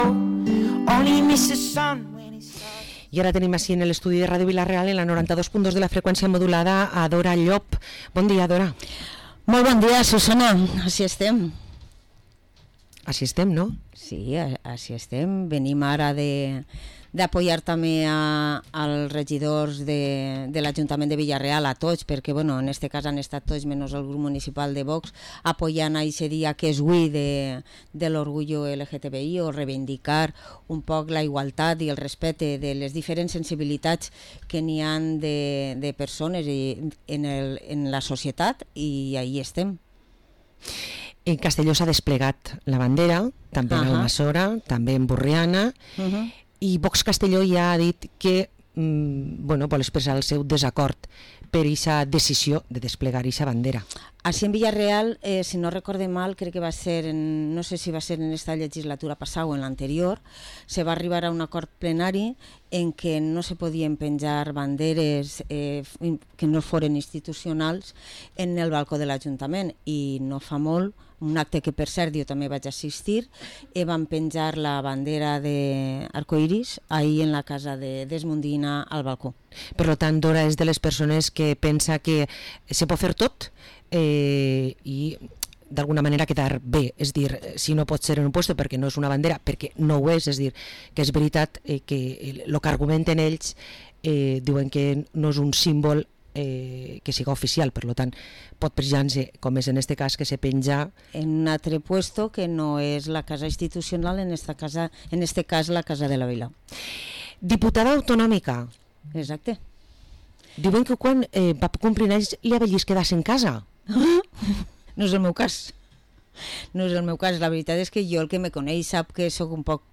Entrevista a Dora Llop, diputada autonòmica del PP a Les Corts Valencianes